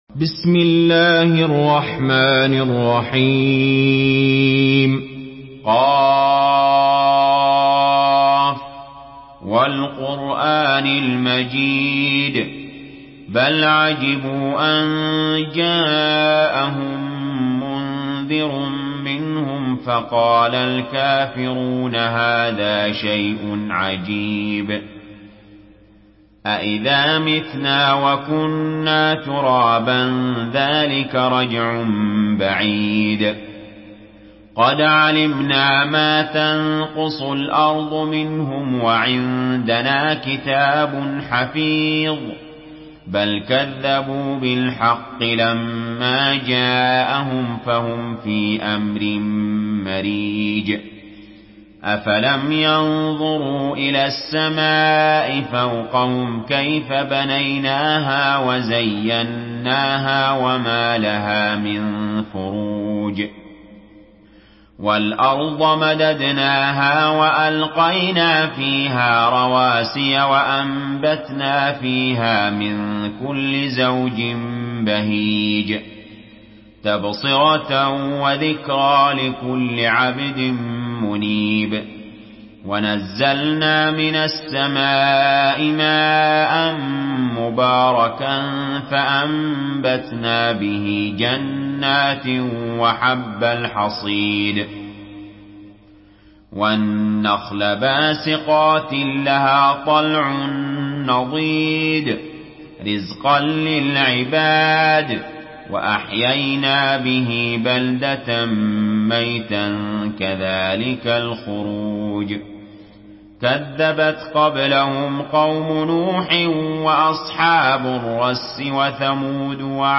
Surah Qaf MP3 by Ali Jaber in Hafs An Asim narration.
Murattal Hafs An Asim